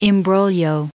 added pronounciation and merriam webster audio
1871_imbroglio.ogg